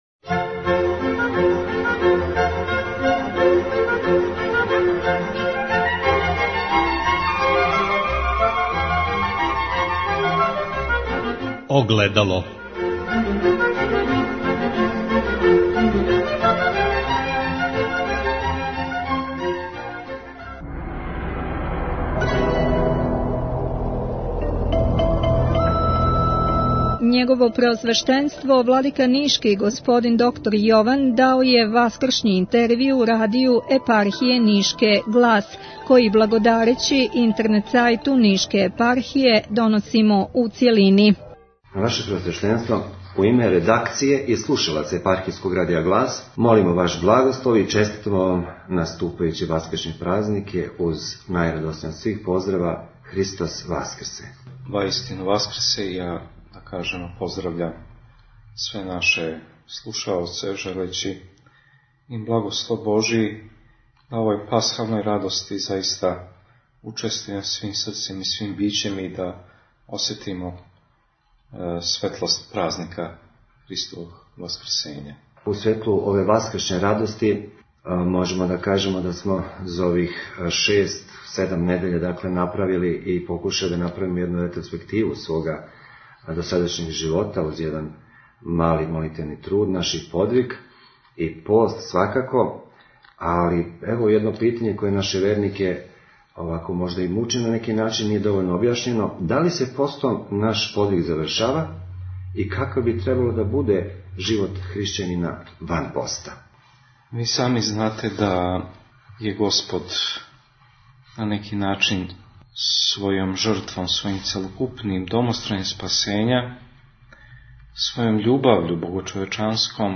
Васкршњи интервју Епископа Нишког Јована Радију "Глас" Епархије Нишке | Радио Светигора